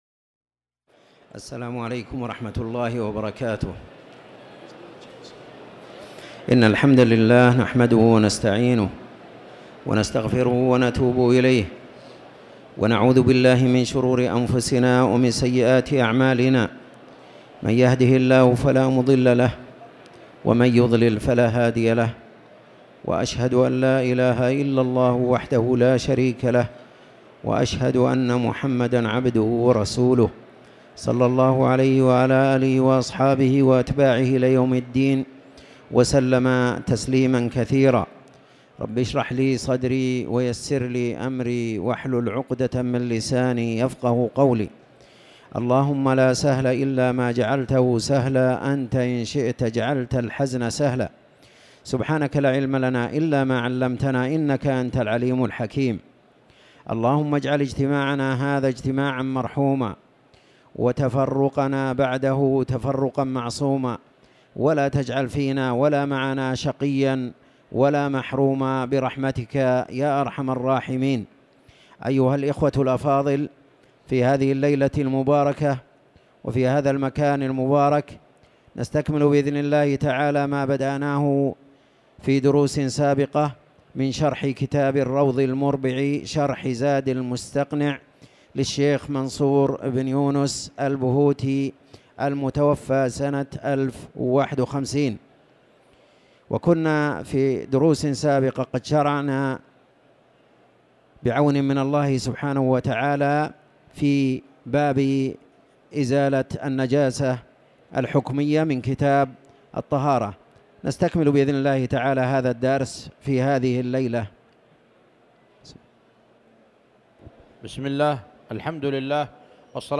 تاريخ النشر ١١ رجب ١٤٤٠ هـ المكان: المسجد الحرام الشيخ